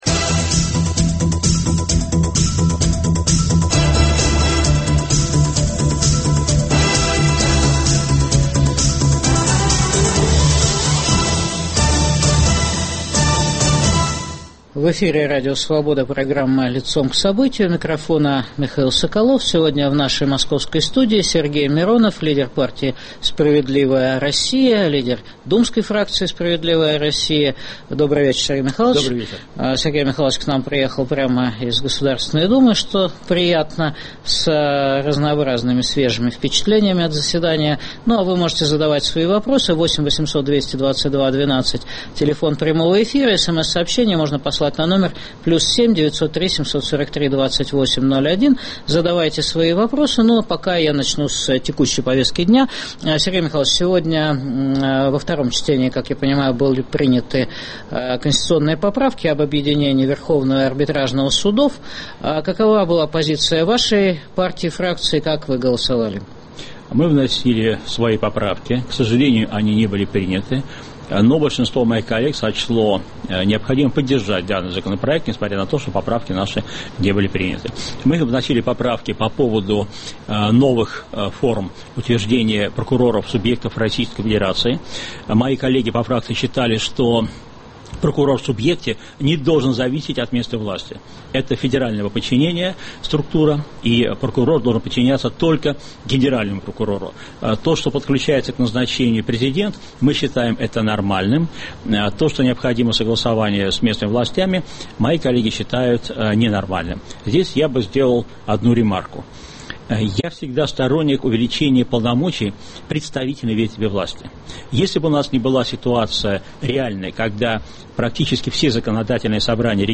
В программе - Сергей Миронов, лидер партии и фракции «Справедливая Россия» в парламенте России.